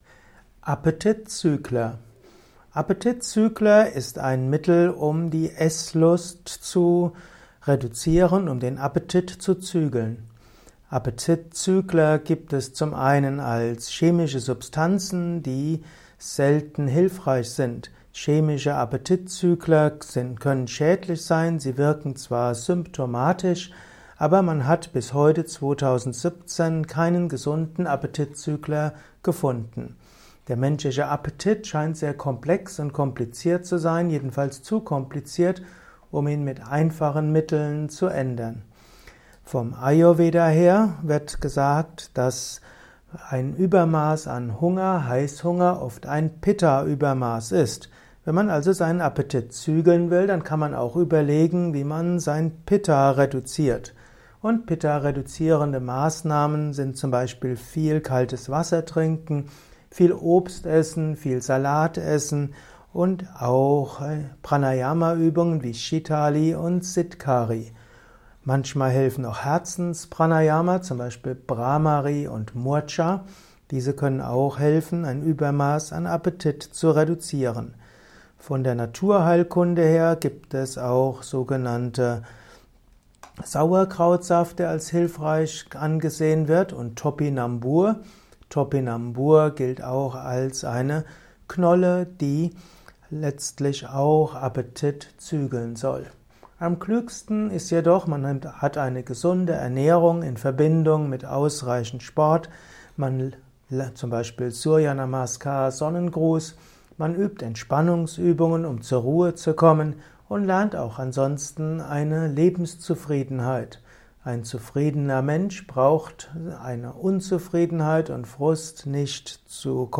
Informationen zu dem Begriff Appetitzügler in diesem Kurzvortrag